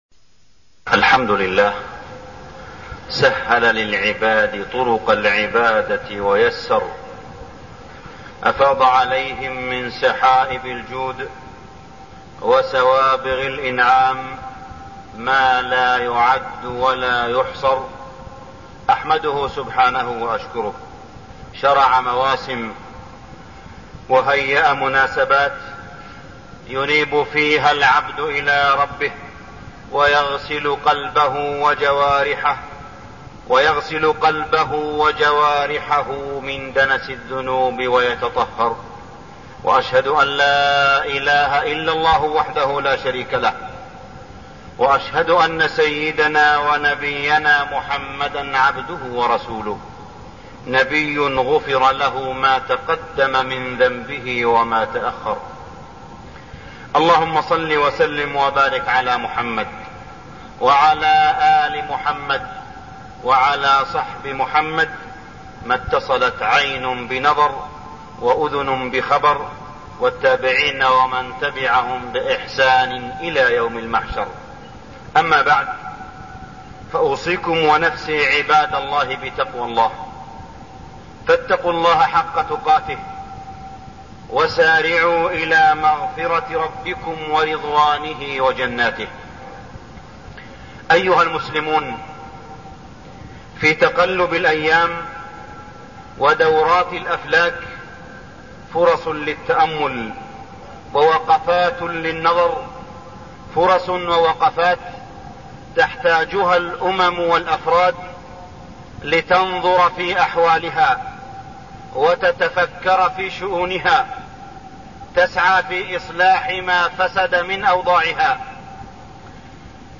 تاريخ النشر ١٥ جمادى الأولى ١٤١١ هـ المكان: المسجد الحرام الشيخ: معالي الشيخ أ.د. صالح بن عبدالله بن حميد معالي الشيخ أ.د. صالح بن عبدالله بن حميد طغيان الشهوات